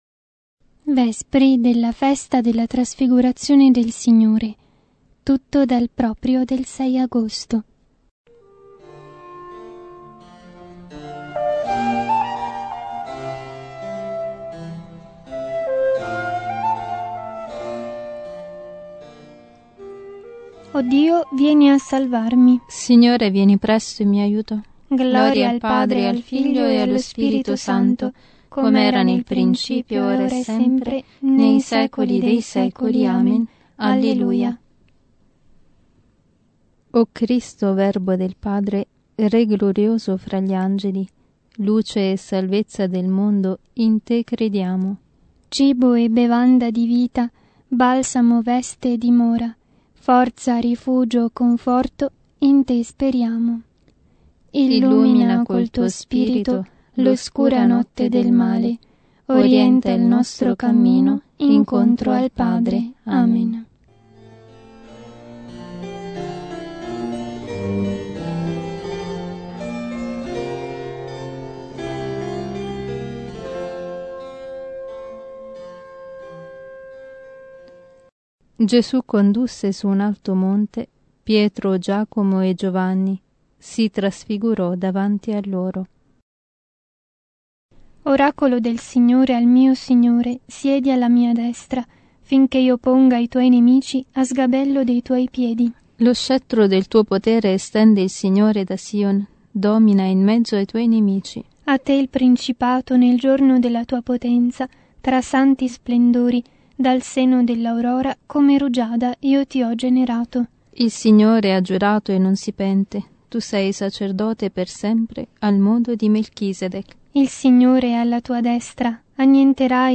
Vespri-Trasfigurazione.mp3